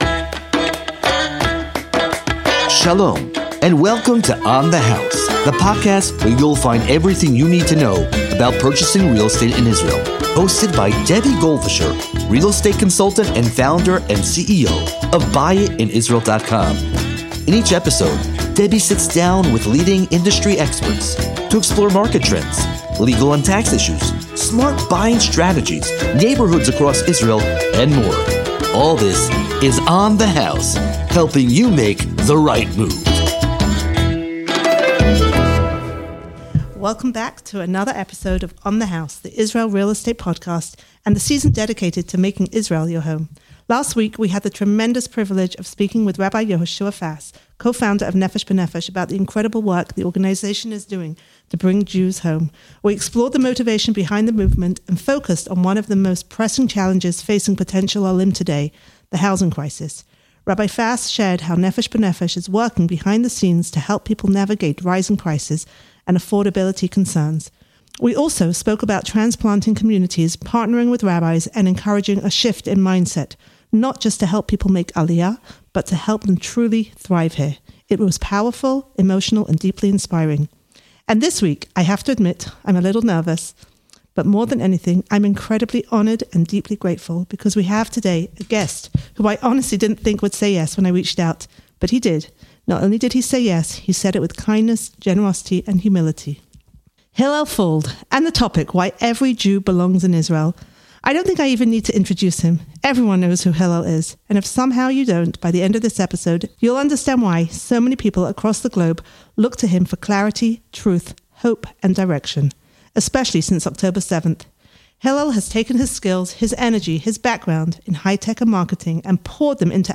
This isn’t just a conversation — it’s a wake-up call.